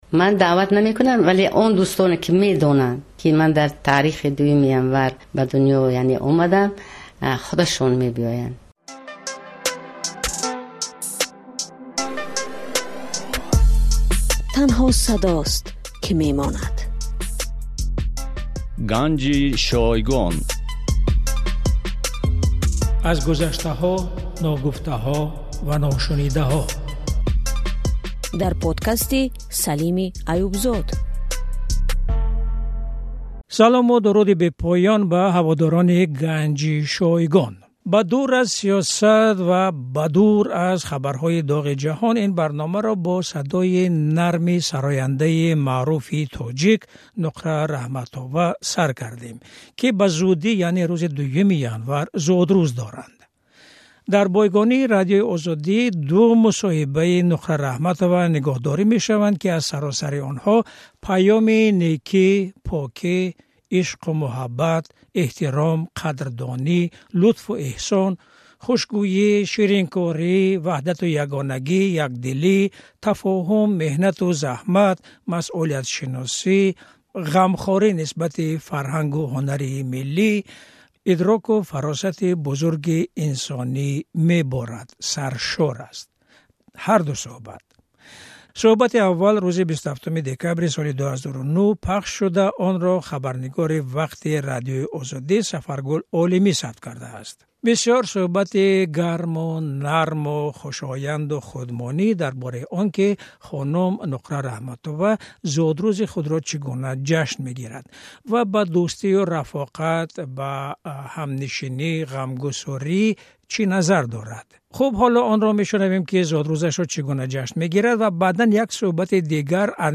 Сарояндаи машҳури тоҷик Нуқра Раҳматова ду дафъа ба Радиои Озодӣ мусоҳиба додааст. Дар онҳо чанд нуктаи пурарзиши таърихӣ, ҳунарӣ ва инсонӣ ҷой доранд.